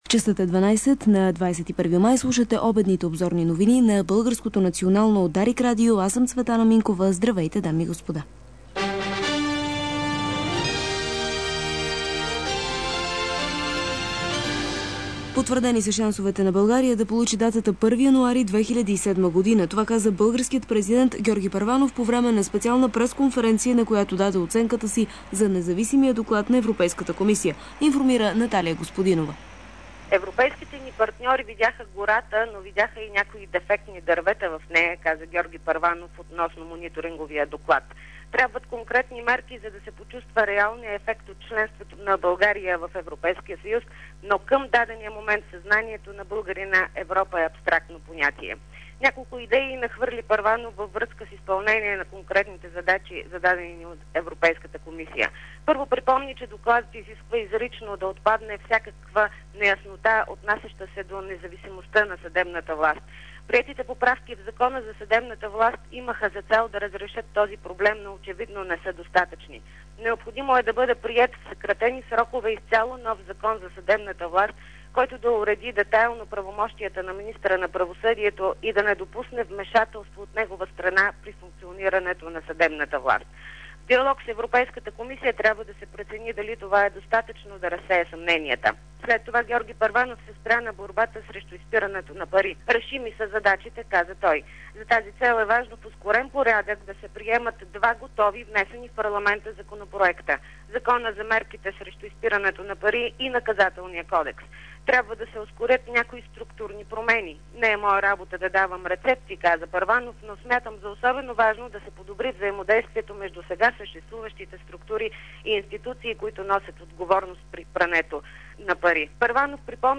DarikNews audio: Обедна информационна емисия – 21.05.2006